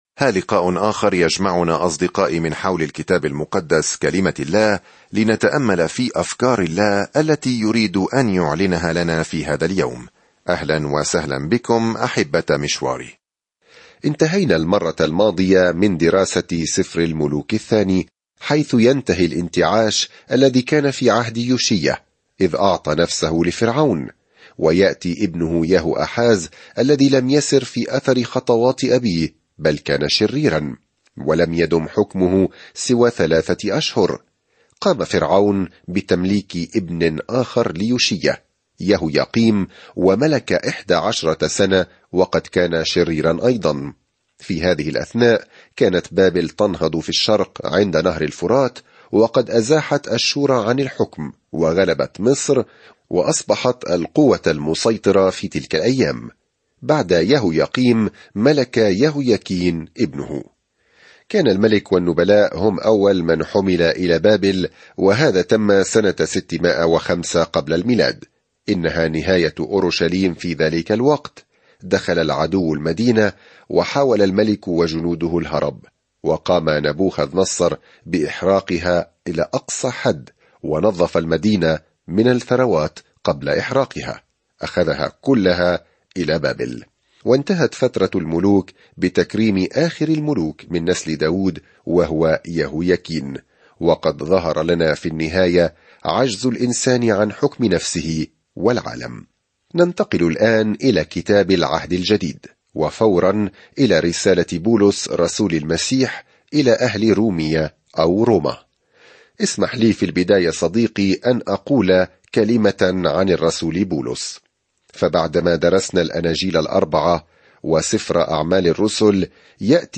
الكلمة رُومِيَةَ 1 ابدأ هذه الخطة يوم 2 عن هذه الخطة الرسالة إلى أهل رومية تجيب على السؤال: "ما هي البشارة؟" وكيف يمكن لأي شخص أن يؤمن، ويخلص، ويتحرر من الموت، وينمو في الإيمان. سافر يوميًا عبر رسالة رومية وأنت تستمع إلى الدراسة الصوتية وتقرأ آيات مختارة من كلمة الله.